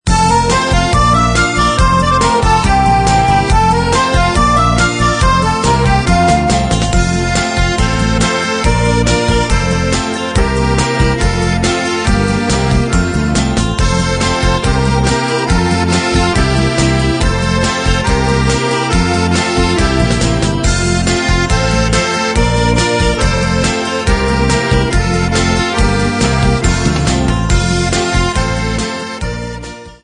Besetzung: Akkordeon mit CD